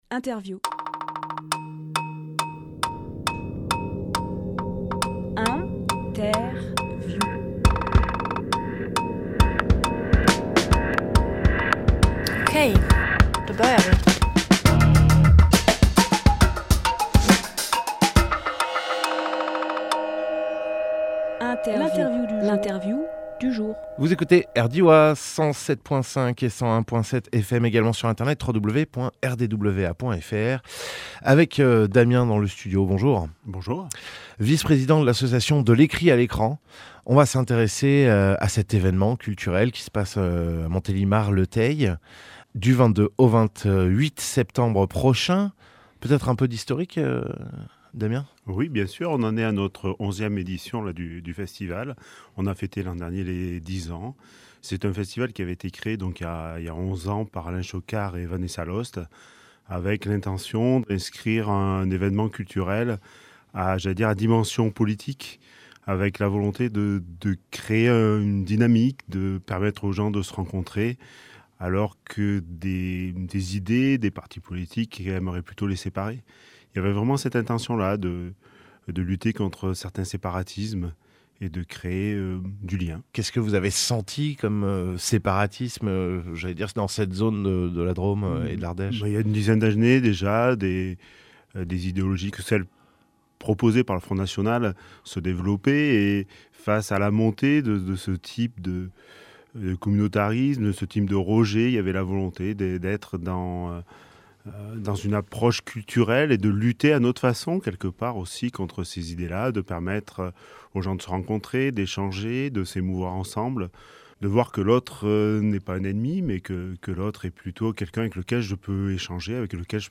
RADIO